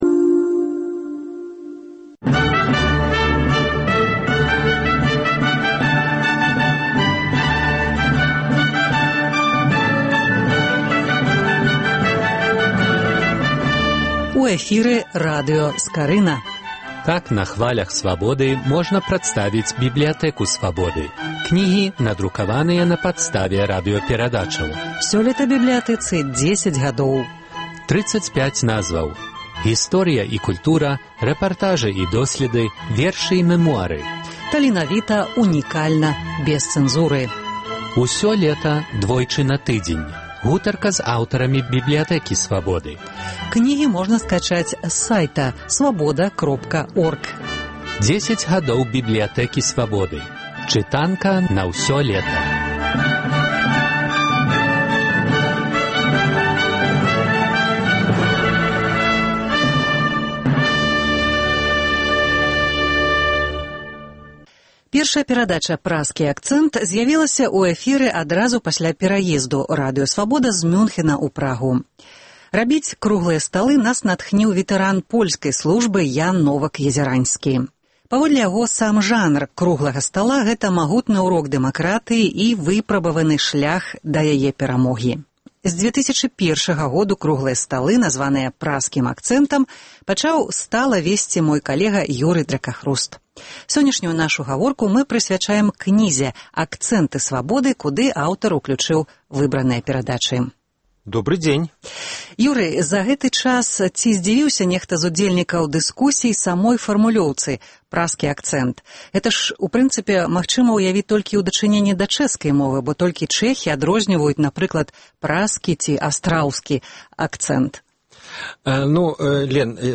Працяг радыёсэрыі “10 гадоў “Бібліятэкі Свабоды”. Гутарка